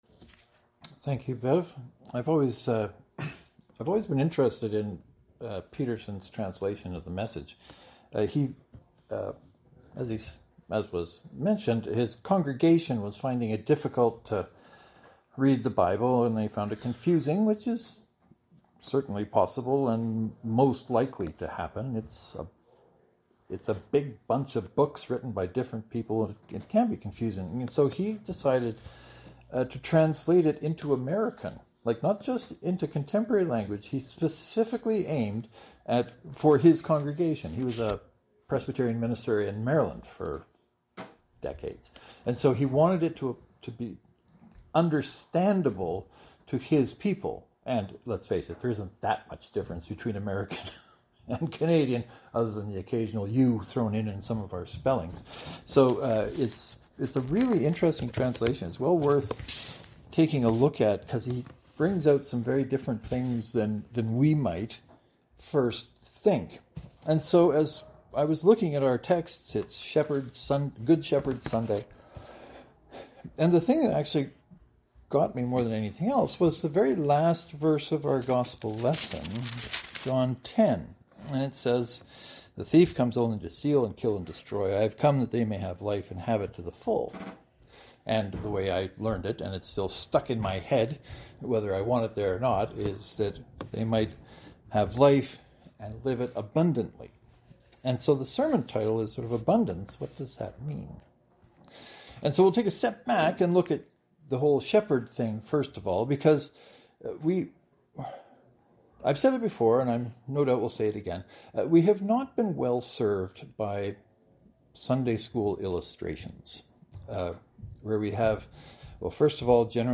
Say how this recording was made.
This Sunday is frequently called the Good Shepherd Sunday.